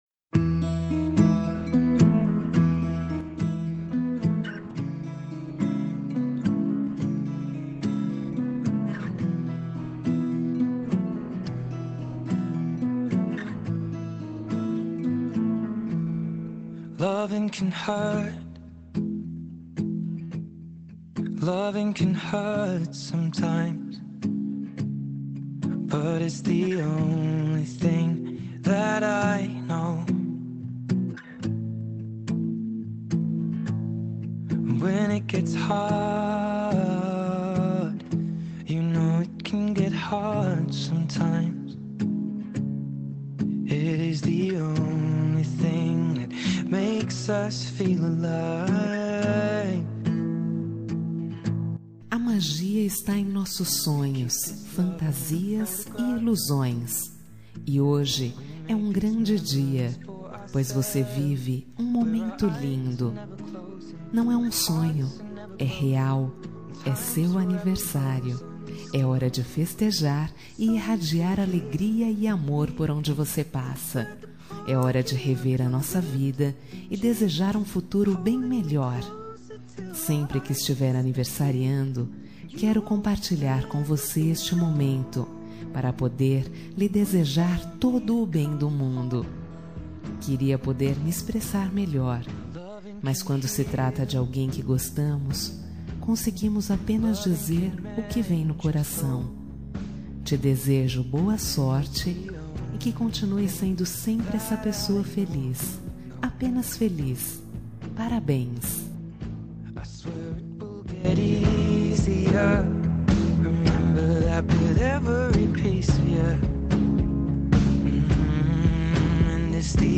Aniversário de Ficante – Voz Feminina – Cód: 8873
aniv-ficante-fem-8873.m4a